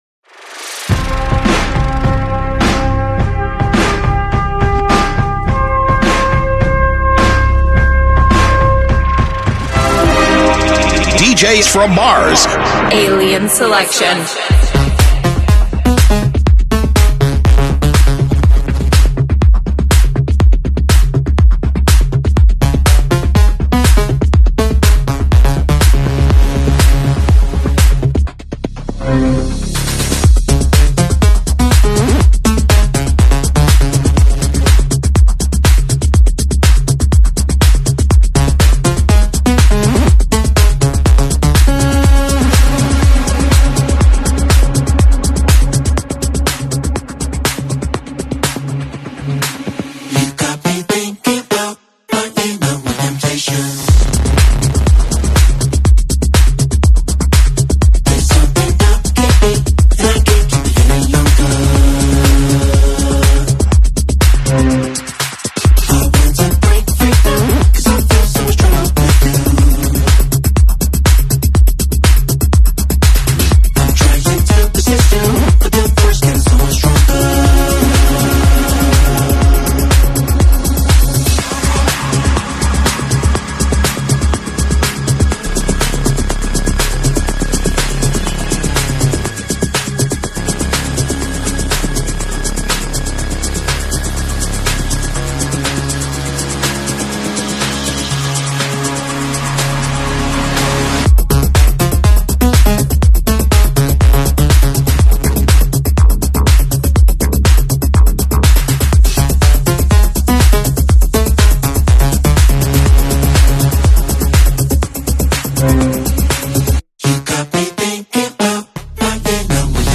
House mix